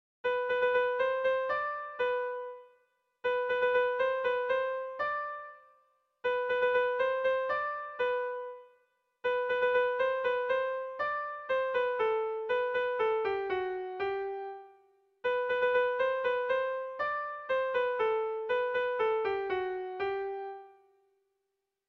Bertso melodies - View details   To know more about this section
Dantzakoa
Lauko berdina, 2 puntuz eta 8 silabaz (hg) / Bi puntuko berdina, 16 silabaz (ip)